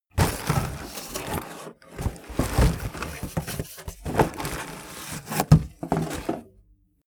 Gemafreie Sounds: Wohnen